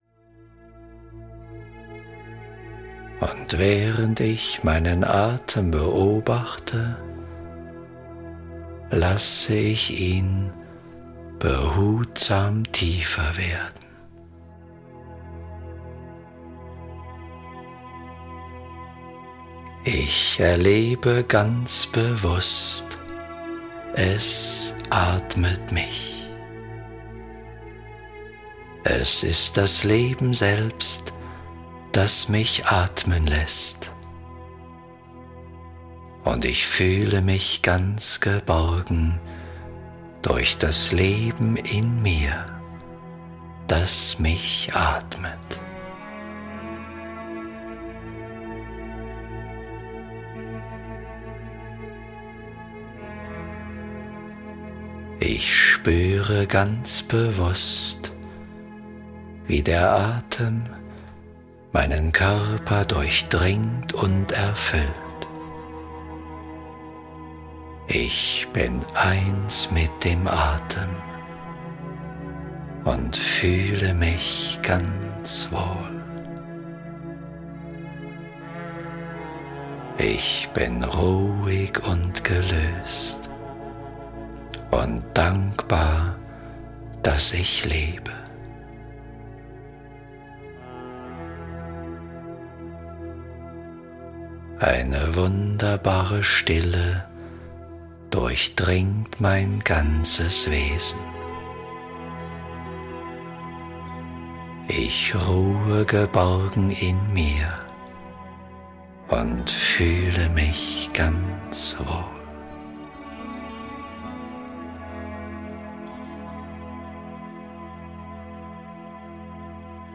Mental Coach: Ganzheitliches Management - Kurt Tepperwein - Hörbuch